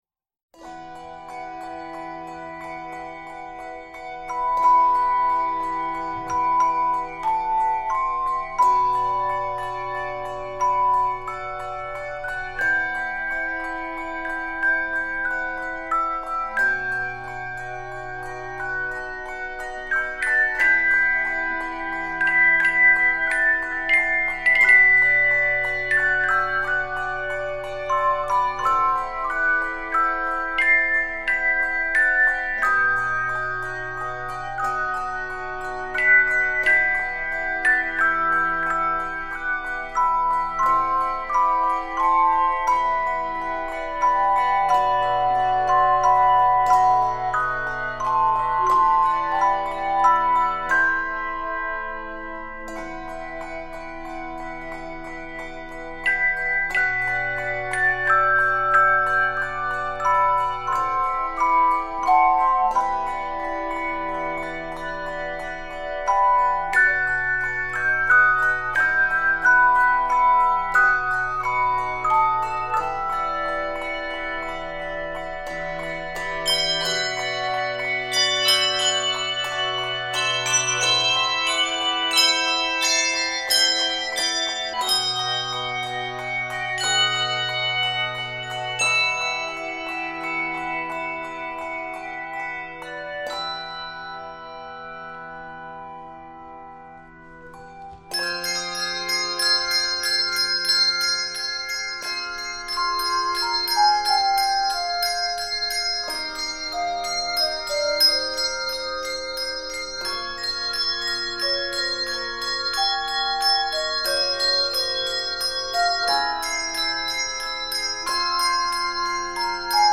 Keys of e minor, f minor, and F Major.